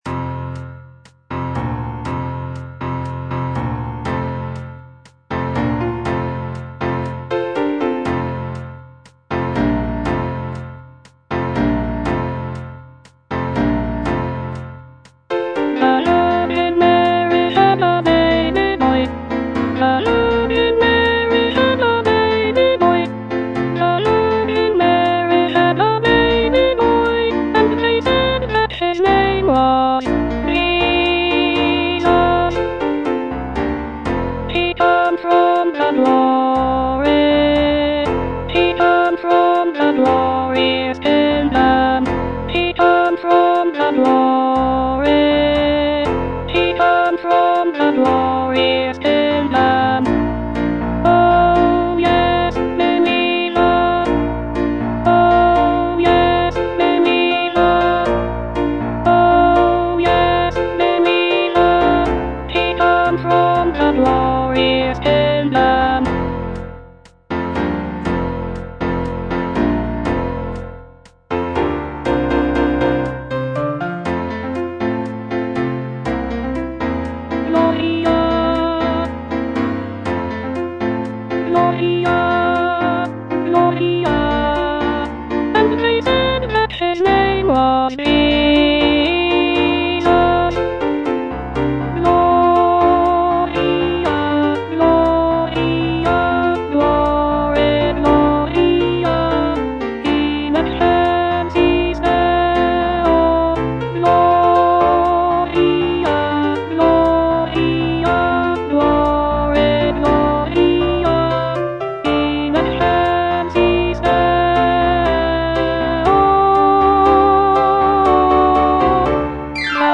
Alto (Voice with metronome)
is a vibrant and energetic arrangement
" set to a lively calypso rhythm.